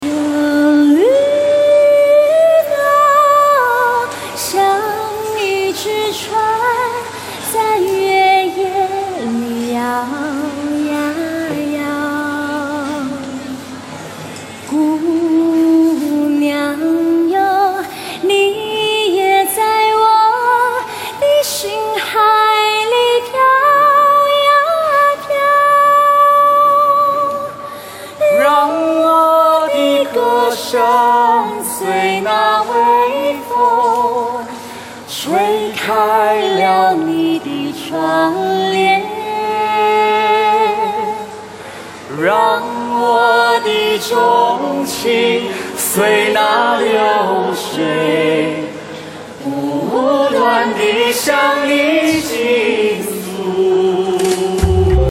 Flash Mob Chorus
2013-07-08一群喜好音樂的志願者在台北101的美食街快閃表演了美麗動人的台灣和中國歌曲
我們帶給了現場群眾意外的驚喜，也希望可以跟全世界介紹台灣的美。